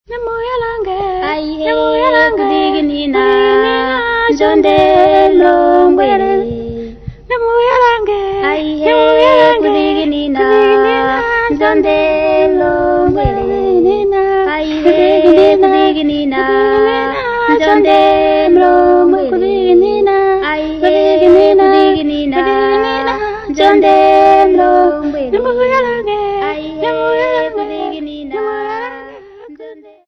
2 Young Ladies
Ovambo Folk
Choral music
Field recordings
Africa Namibia Walvis Bay f-sx
sound recording-musical
Indigenous music.
7.5 inch reel